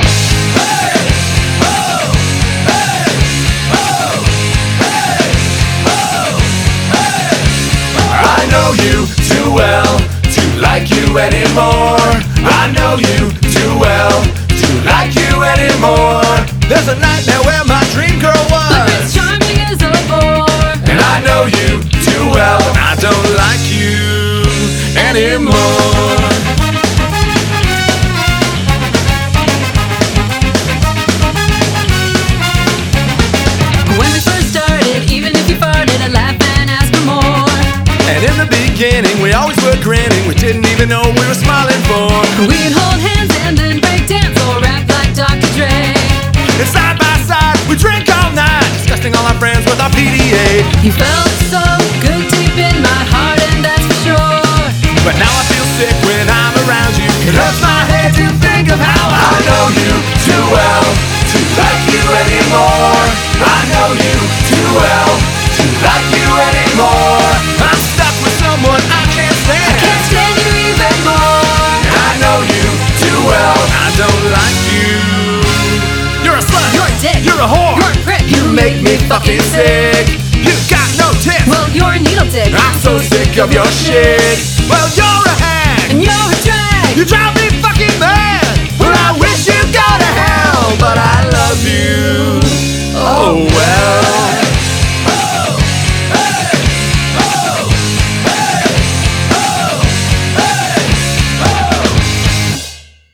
BPM236
Audio QualityPerfect (High Quality)
It's a ska duet, of course it needs a couples chart.